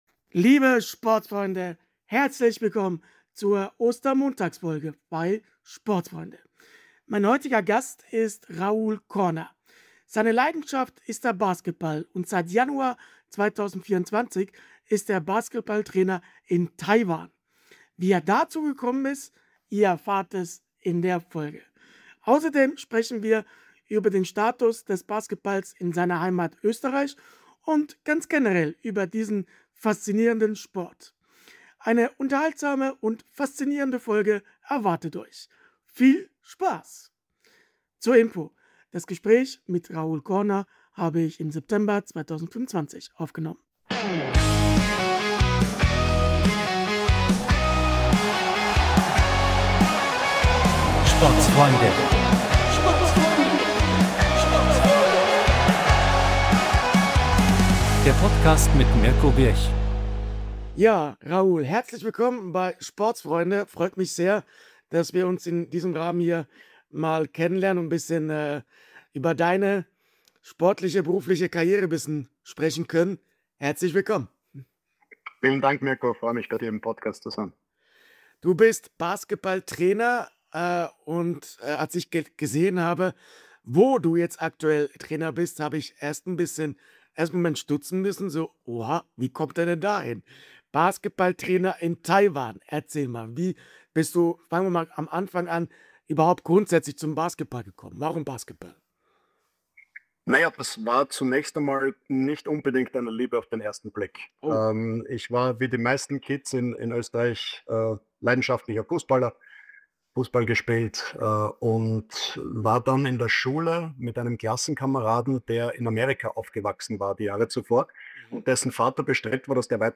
Frohen Ostermontag, liebe SPORTSFREUNDE!